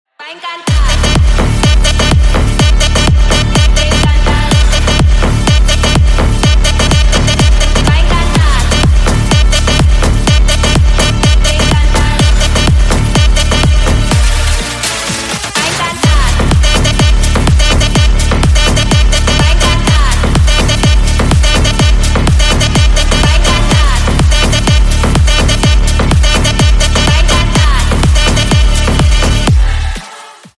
# Клубные Рингтоны » # Громкие Рингтоны С Басами
# Рингтоны Электроника